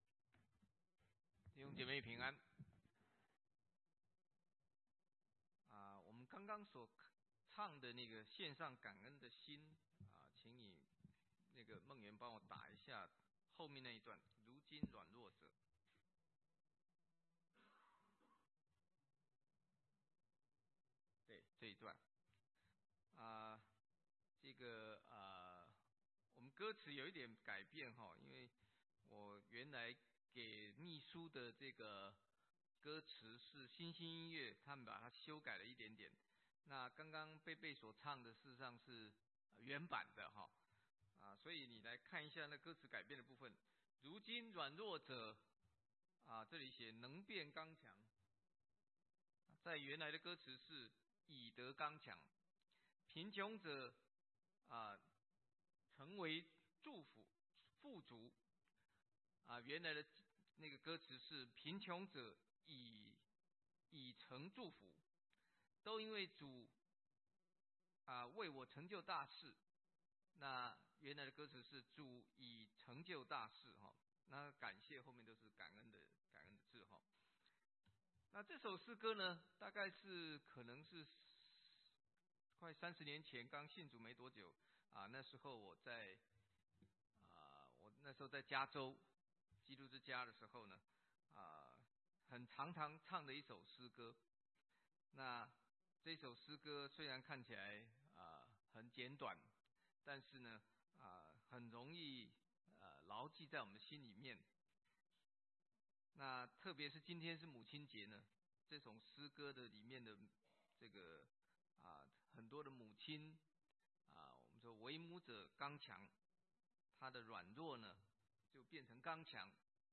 華埠國語堂